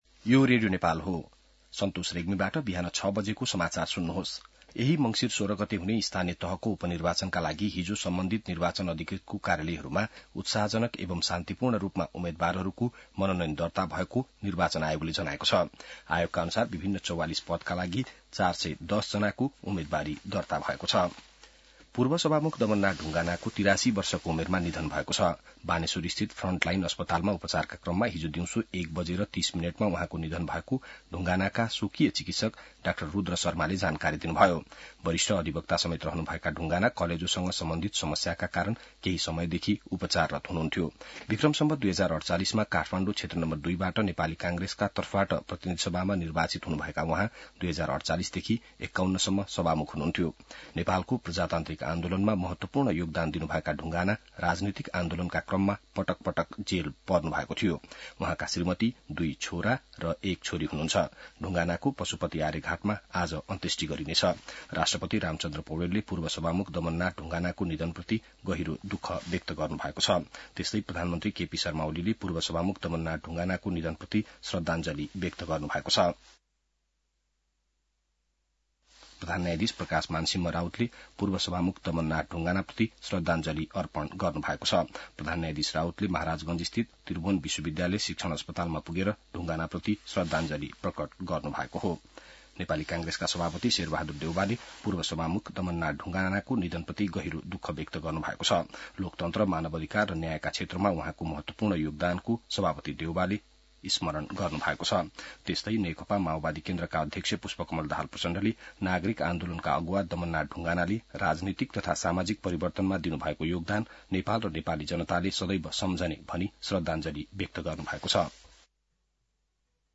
बिहान ६ बजेको नेपाली समाचार : ४ मंसिर , २०८१